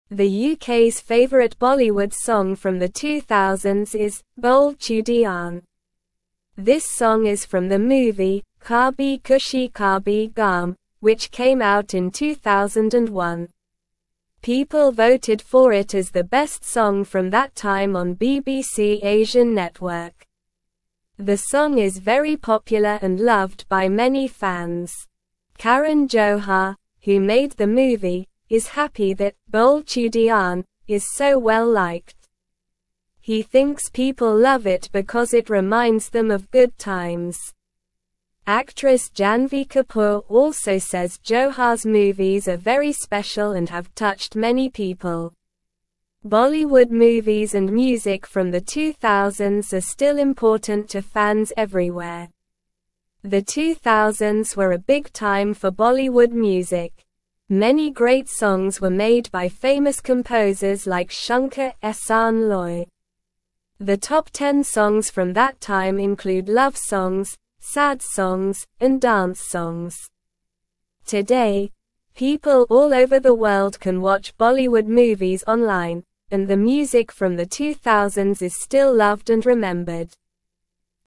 Slow
English-Newsroom-Lower-Intermediate-SLOW-Reading-Bole-Chudiyan-A-Popular-Bollywood-Song.mp3